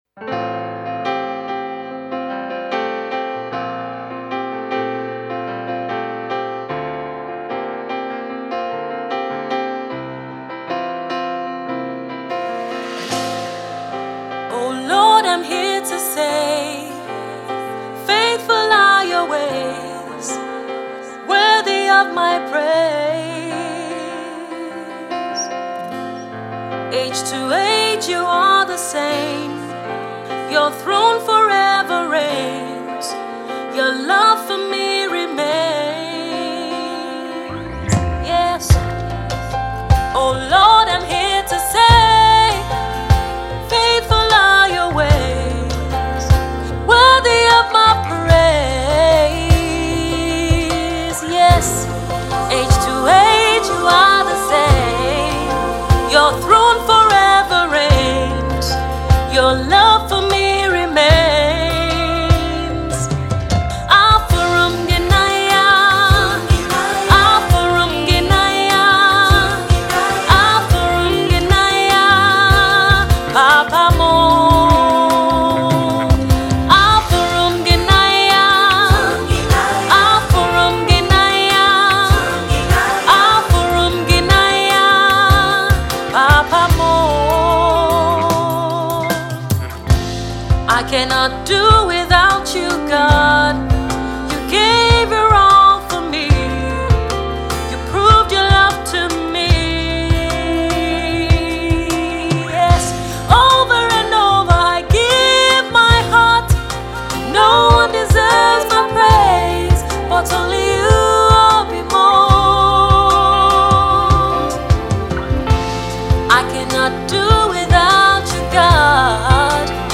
soulful single